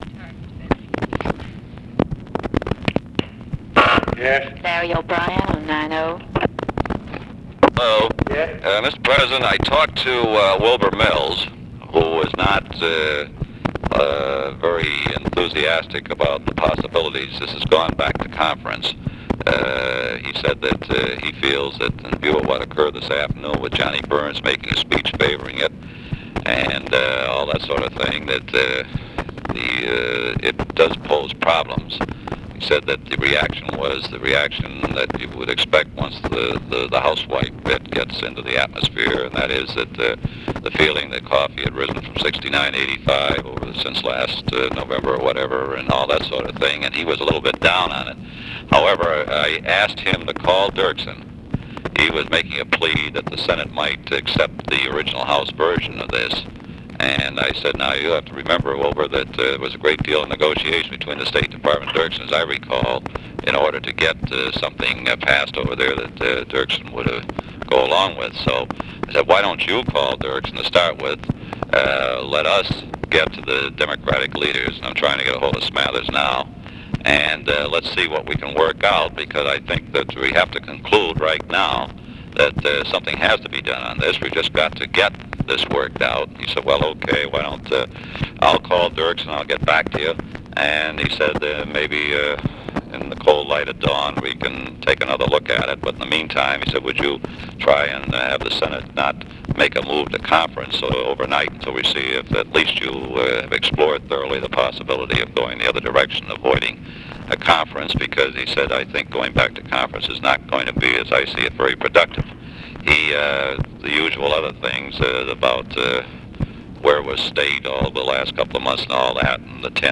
Conversation with LARRY O'BRIEN, August 18, 1964
Secret White House Tapes